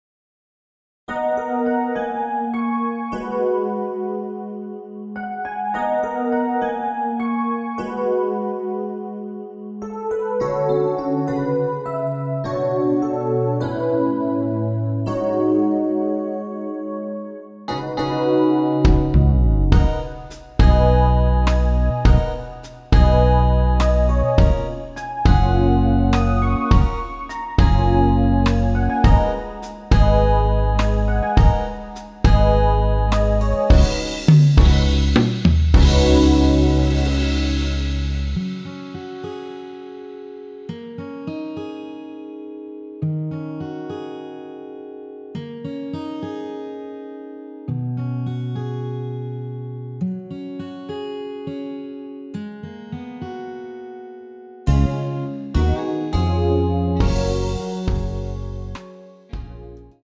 원키에서(+5)올린 MR입니다.(미리듣기 확인)
F#
앞부분30초, 뒷부분30초씩 편집해서 올려 드리고 있습니다.
중간에 음이 끈어지고 다시 나오는 이유는